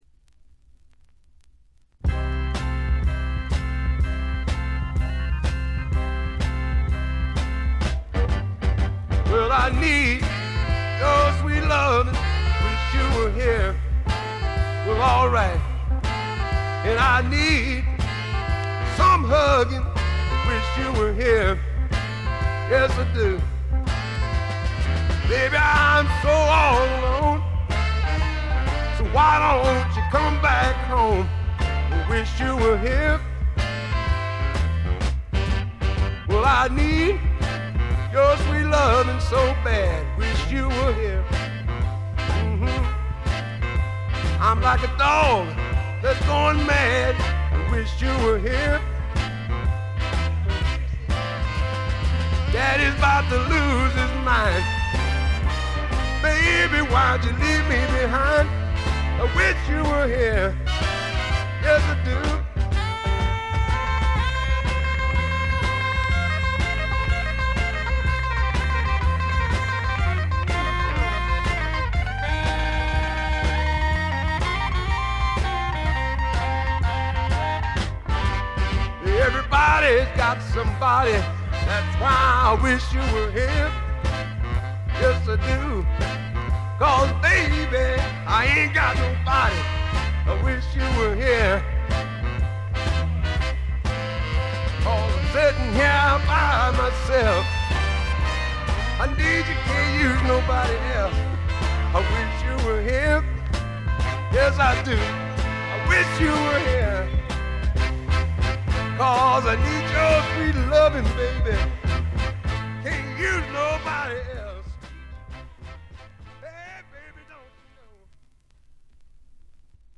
テキサス産スワンプポップの名作。
試聴曲は現品からの取り込み音源です。